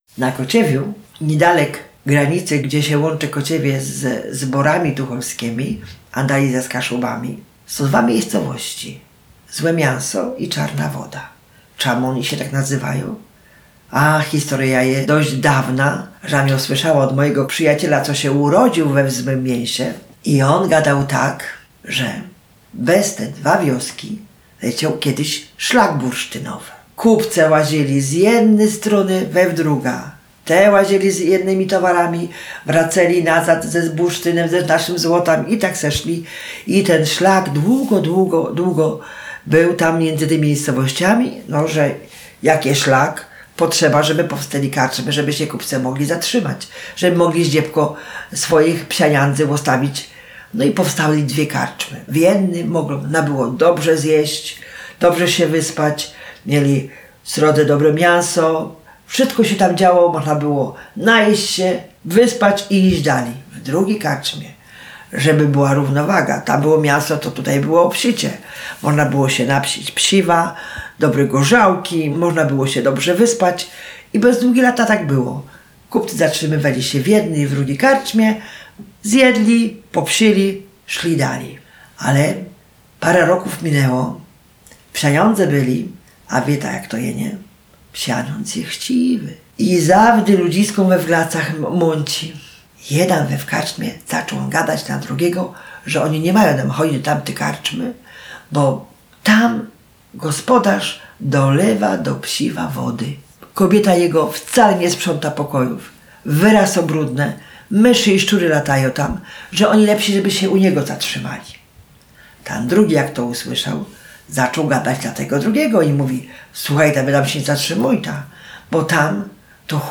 Bajka „O dwóch karczmach”.